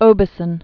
(ōbə-sən, -sôɴ)